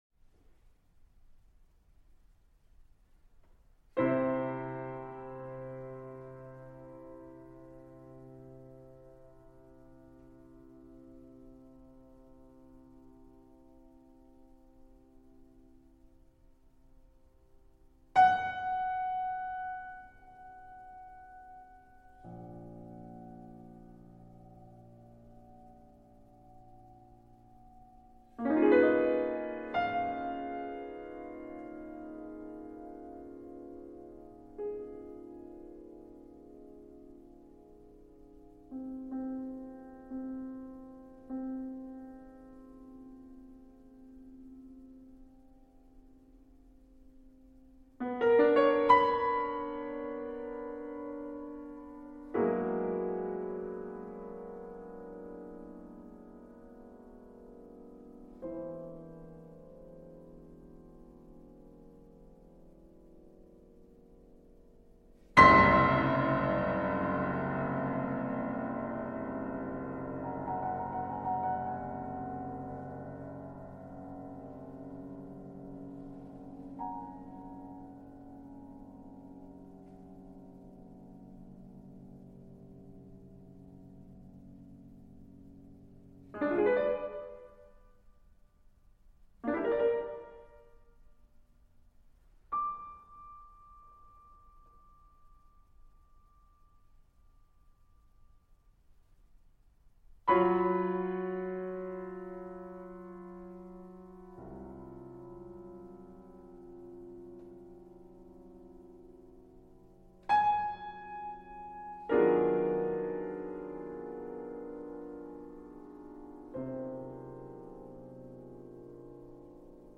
für Klavier solo